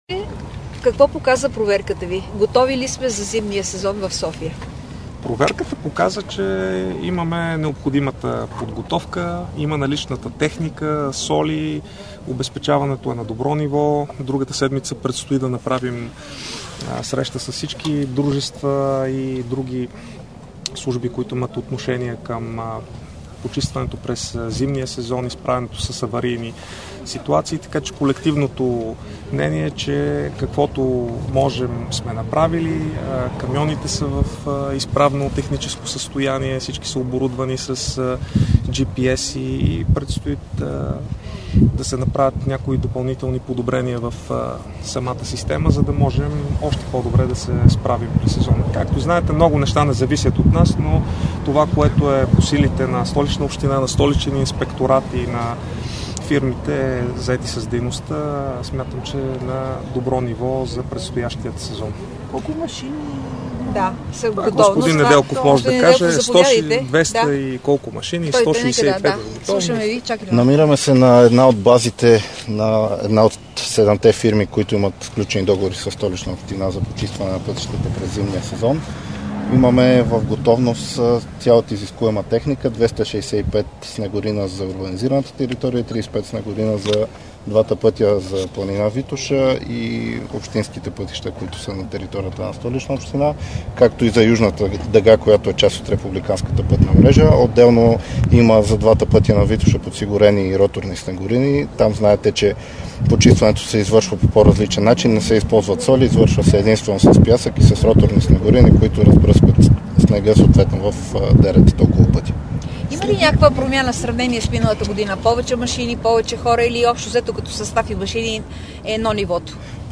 Директно от мястото на събитието